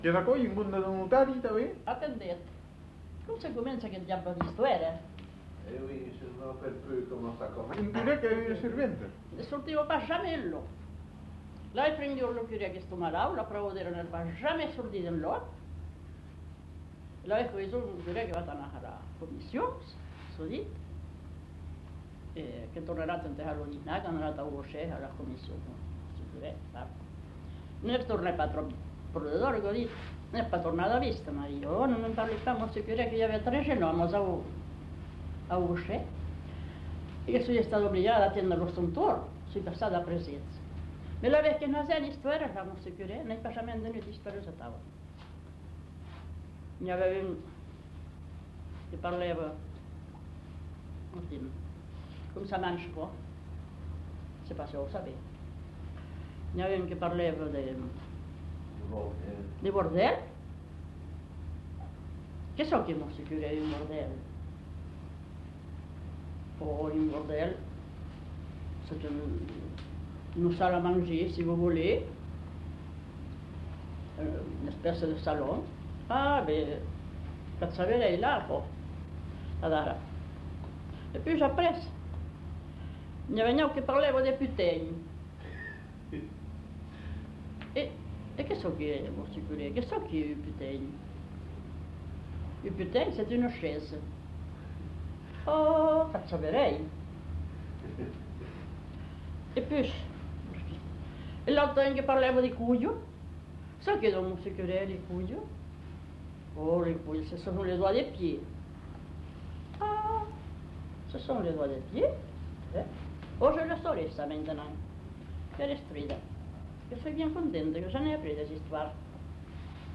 Aire culturelle : Marsan
Lieu : Landes
Genre : conte-légende-récit
Effectif : 1
Type de voix : voix de femme
Production du son : parlé